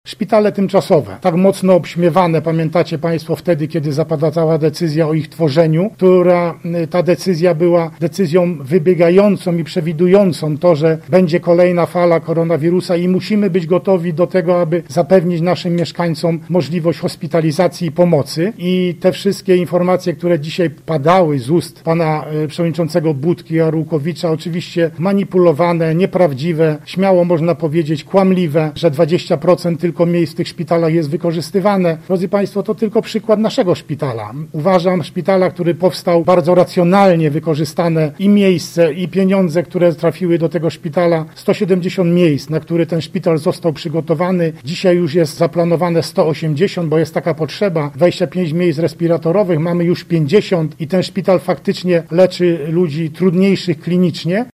Podczas spotkania z dziennikarzami poruszono także kwestie wykorzystania szpitali tymczasowych.